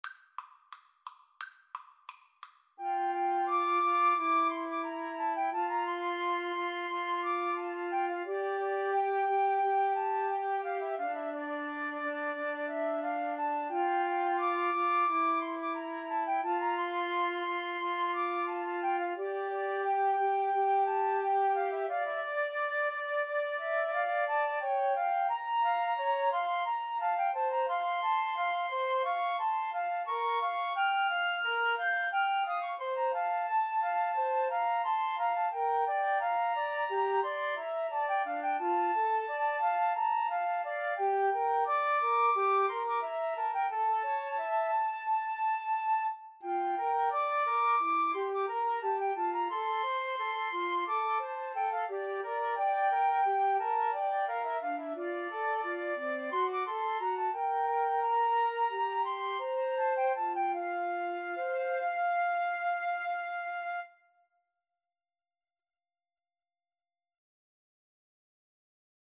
Play (or use space bar on your keyboard) Pause Music Playalong - Player 1 Accompaniment Playalong - Player 3 Accompaniment reset tempo print settings full screen
F major (Sounding Pitch) (View more F major Music for Flute Trio )
Molto allegro =176